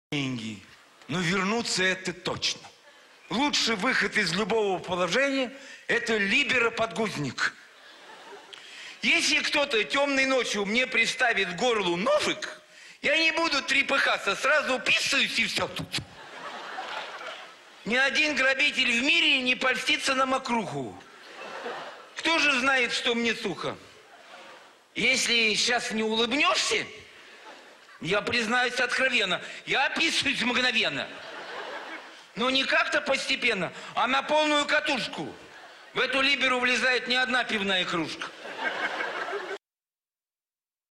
ДОБРЫЙ ДЕНЬ, ДОЛГО ИСКАЛА СТИХОТВОРНОЕ ВЫСТУПЛЕНИЕ ЯНА АРЛАЗОРОВА ПРО РЕКЛАМУ.
С Авторадио!